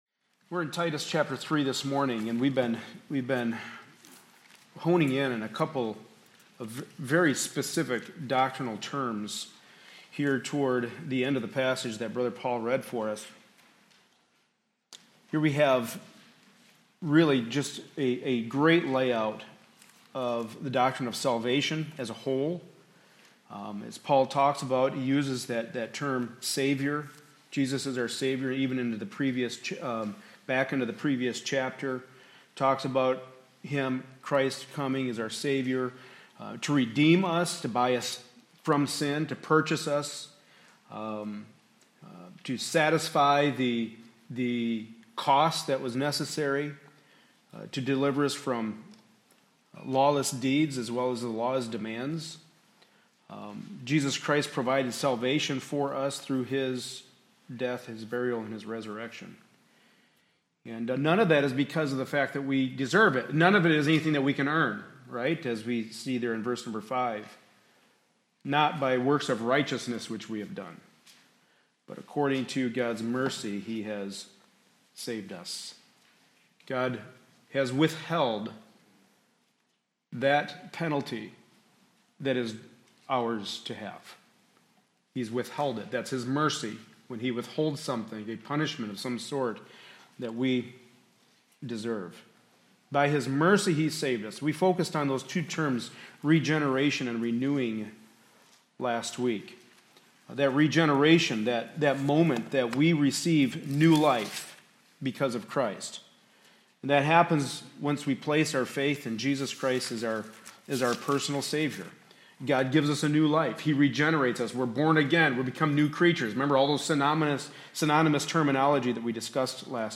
Passage: Titus 3:1-8 Service Type: Sunday Morning Service